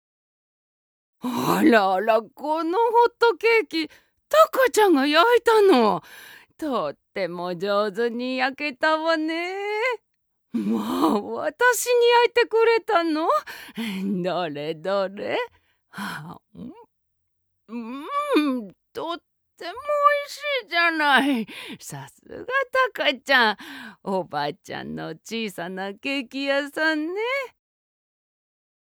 ◆孫が大好きなおばあちゃん◆